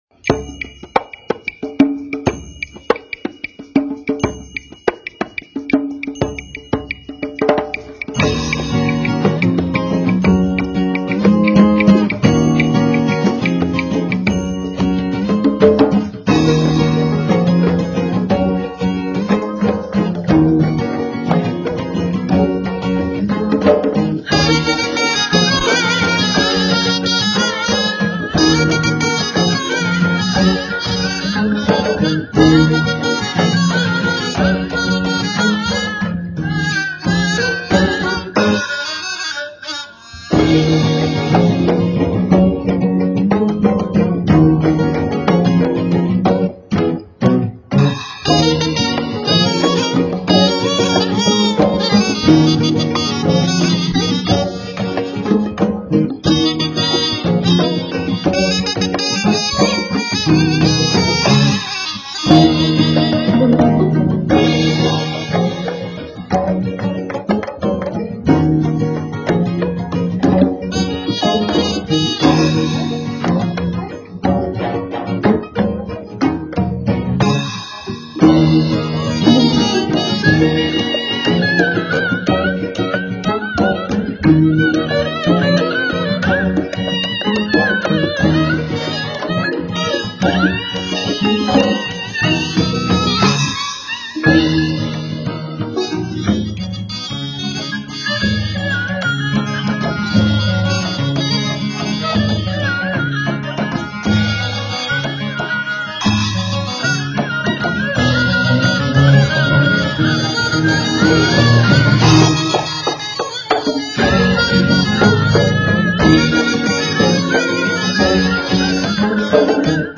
▲ 대학국악제 이번 국악난장 전야제 행사로 국악을 사랑하는 대학생들의 패기와 열정을 엿볼 수 있는 ‘제1회 대한민국 대학국악제’ 본선이 17일 저녁 7시부터 광양시청 앞 야외공연장에서 개최됐다.
참가자들은 전통국악에 현대음악을 가미한 퓨전 국악을 선보이며 관객들의 오감을 만족시켰다.